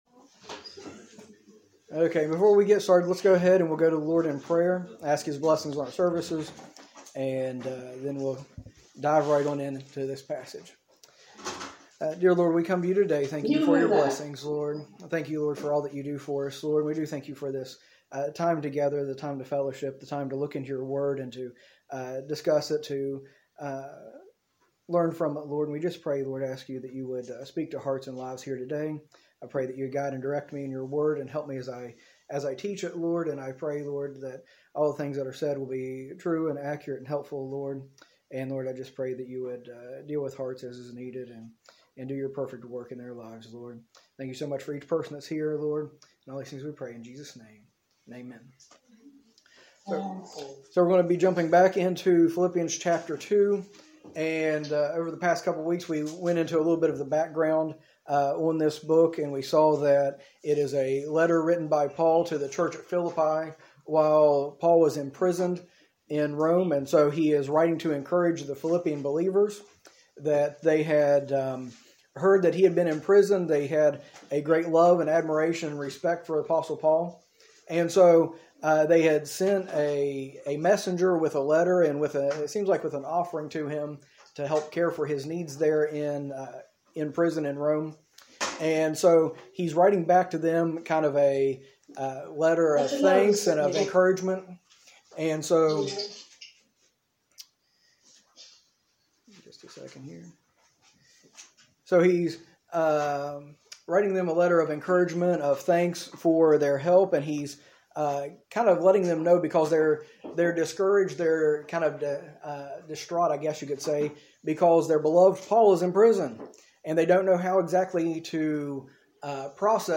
A message from the series "Philippians."